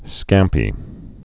(skămpē, skäm-)